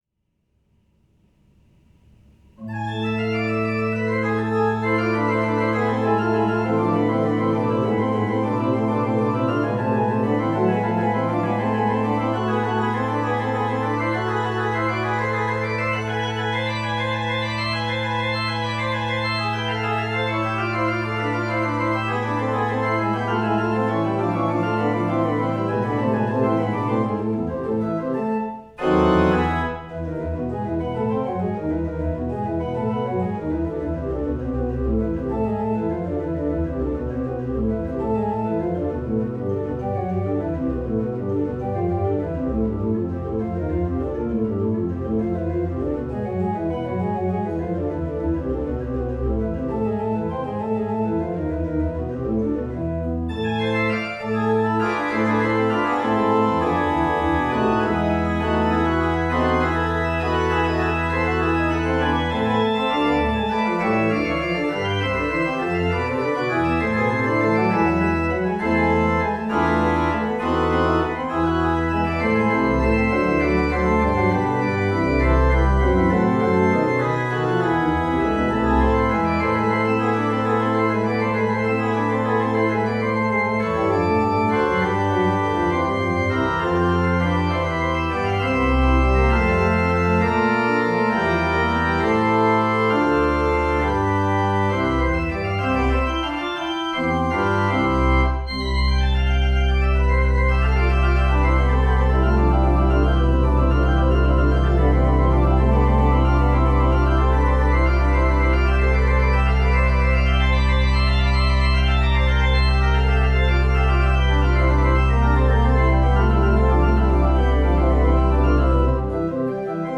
Residence Pipe Organ
These files were recorded with a Sony PCM-M1 DAT Walkman fed by microphones from Core Sound and then transfered via SPDIF to an M-Audio Audiophile 2496 PCI interface.
There are a couple of 'glitches' here and there that I need to investigate that were introduced during the coversion to MP3.